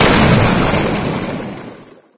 slimey_shotgun_ulti_01.ogg